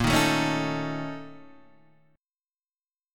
A#mM7bb5 chord {x 1 1 2 2 x} chord